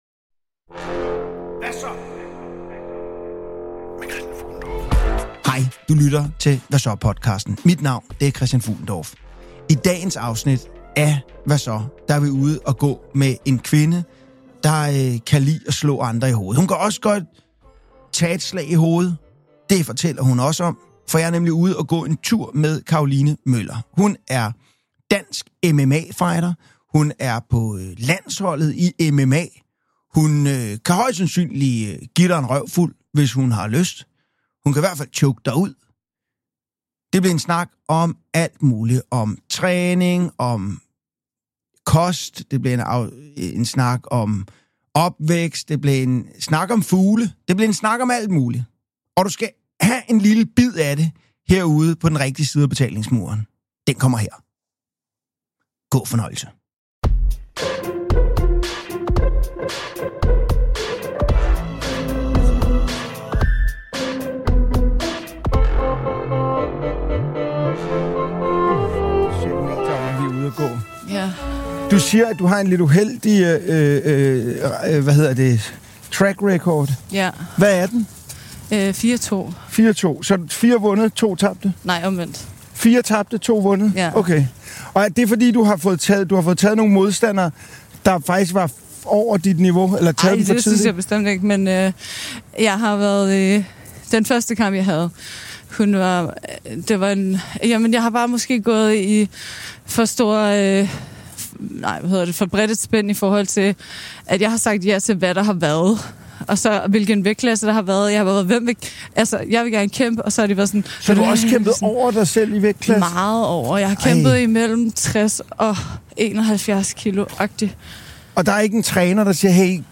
Siden 2017 har jeg gået ture med mennesker jeg beundrer, finder spændende eller bare har noget helt særligt at byde på. Vi har ikke travlt, vi jagter ikke grinet, for det er faktisk ikke en gang et interview, med bare en helt almindelig menneskelig samtale.